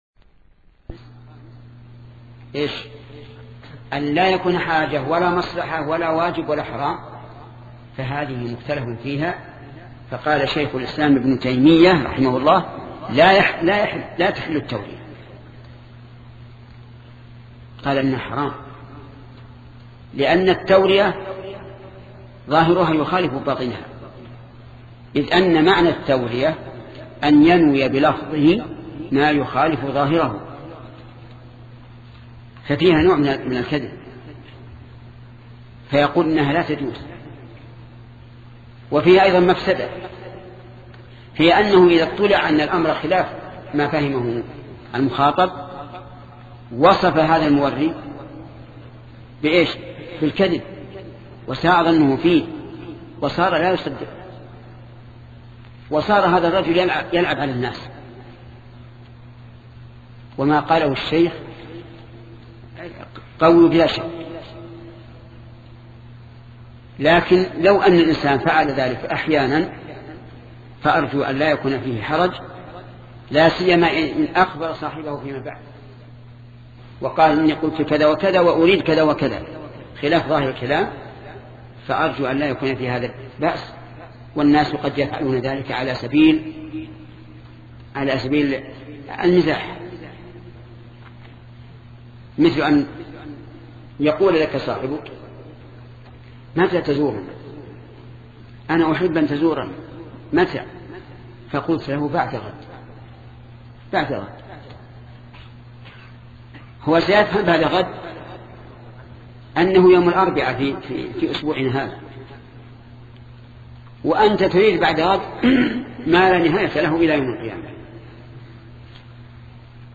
سلسلة مجموعة محاضرات شرح الأربعين النووية لشيخ محمد بن صالح العثيمين رحمة الله تعالى